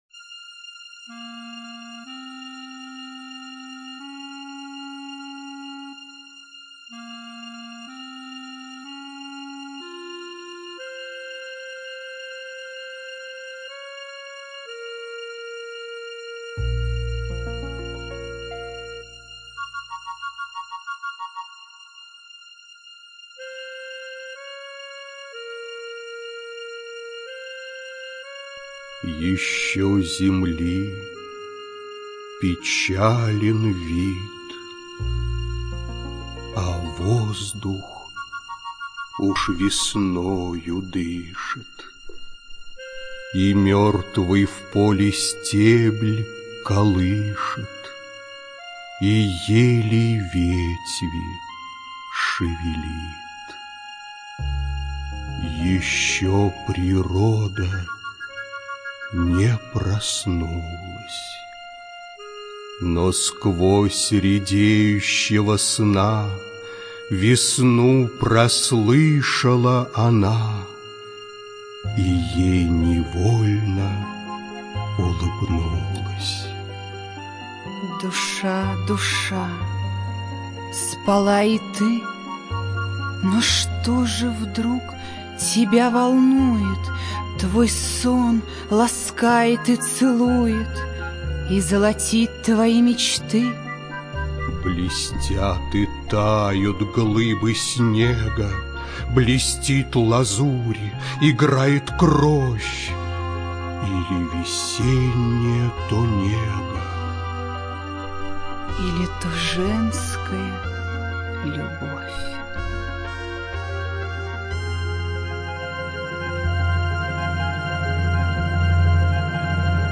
ЧитаетАвтор
ЖанрПоэзия